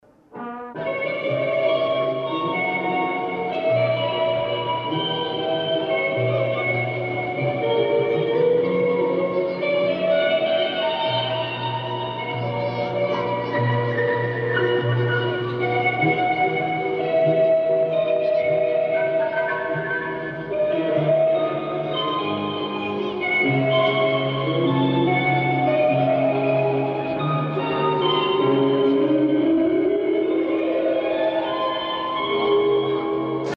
Плохо тема прослушивается.